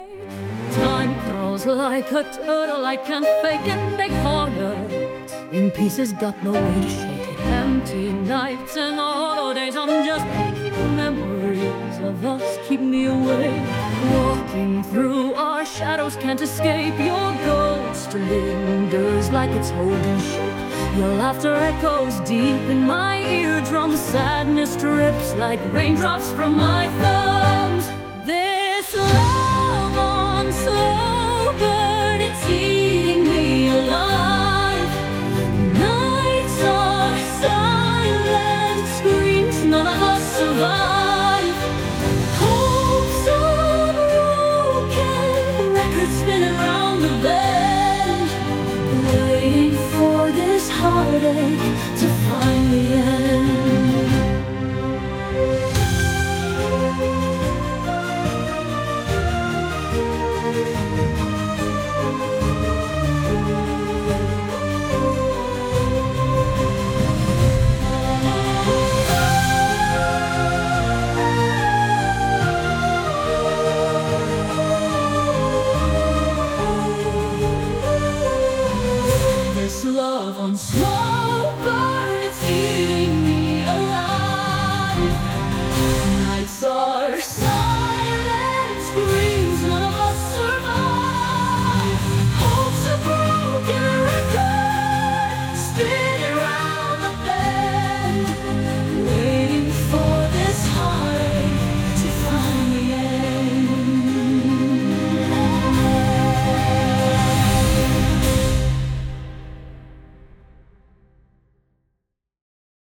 With Vocals / 歌あり